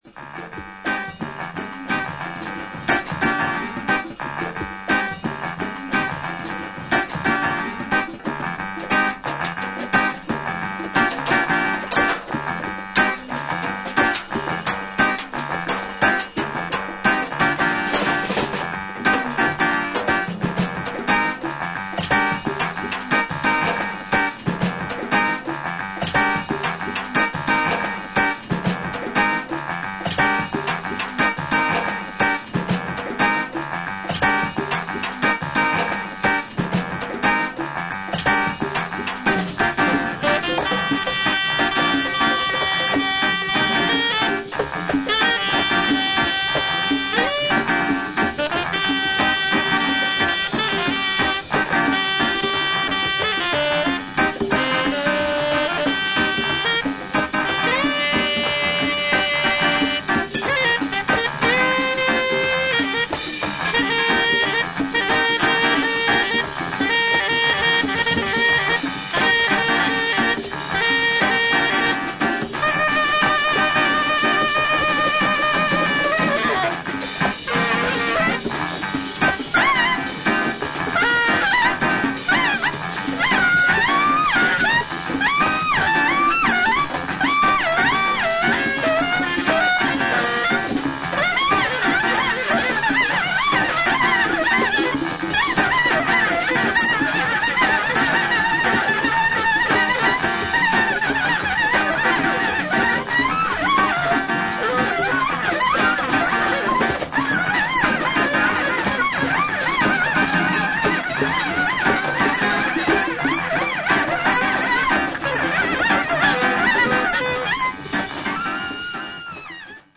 両作ともブギー色の強いダンスフロアに向けた好エディットが収録。